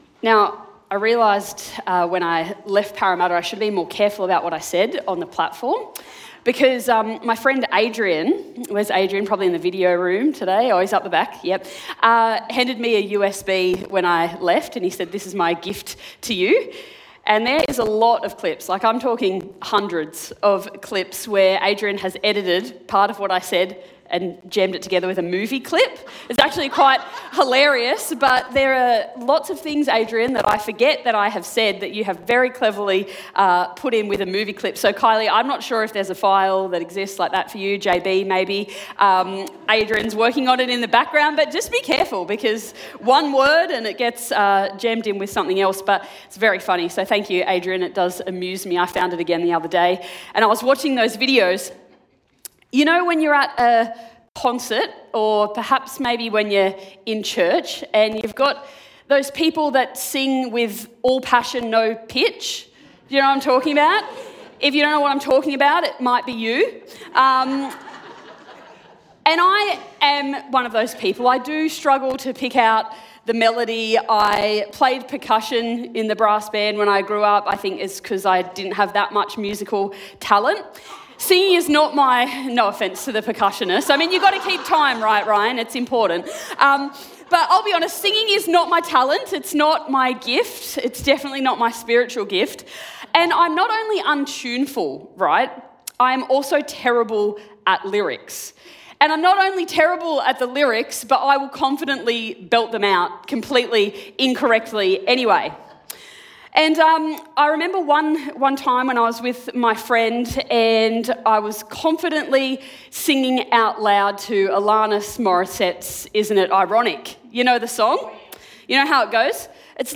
Sermon Podcasts How the Gospel changes everything